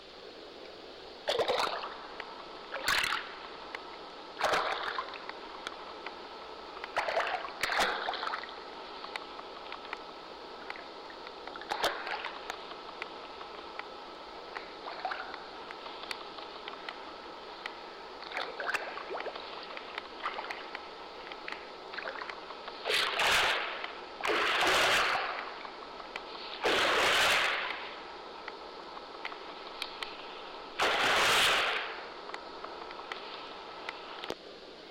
running water
描述：falling water in a pool..
标签： pool splash running water
声道立体声